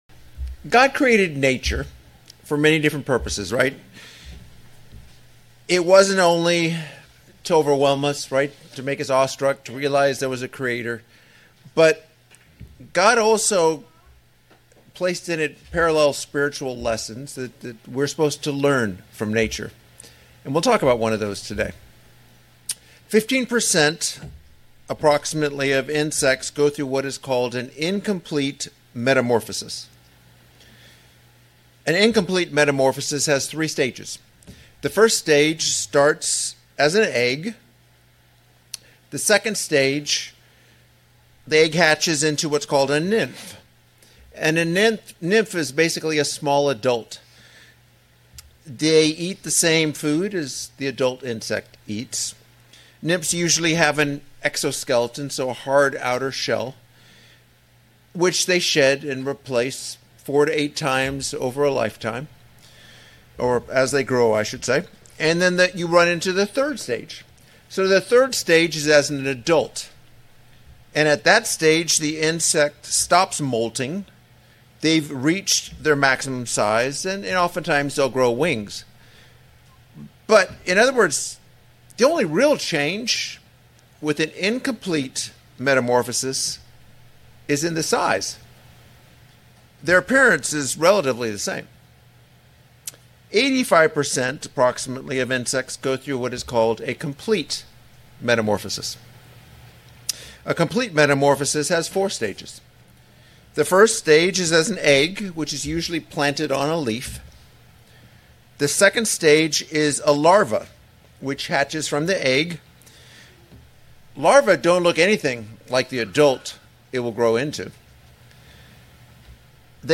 This sermon expounds on our spiritual metamorphosis. We are being transformed from the inside out. We need to allow a full demolition and remodeling of ourselves.